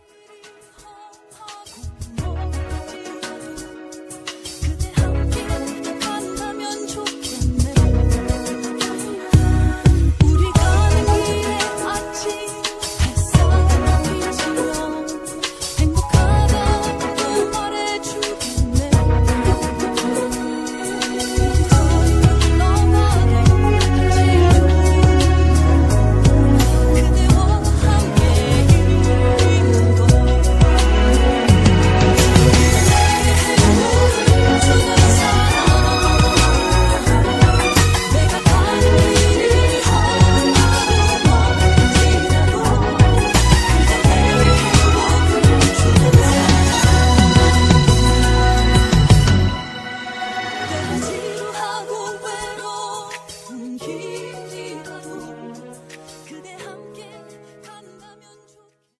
음정 -1키 2:50
장르 가요 구분 Voice MR